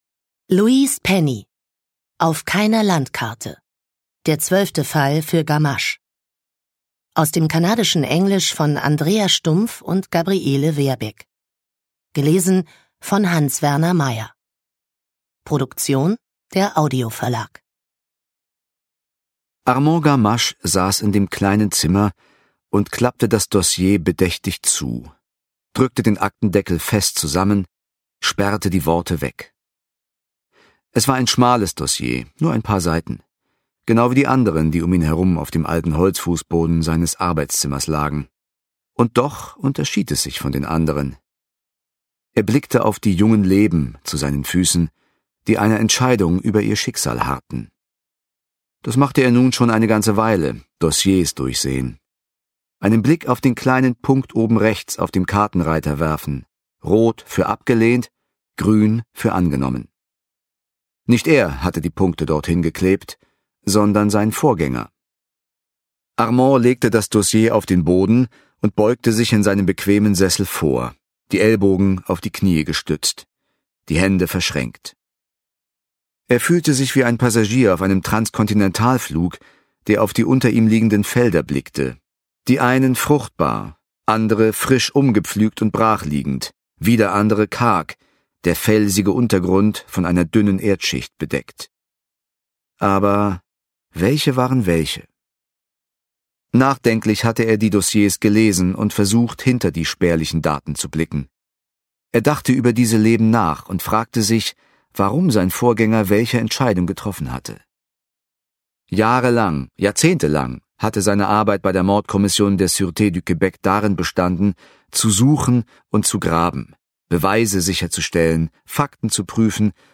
Hans-Werner Meyer (Sprecher)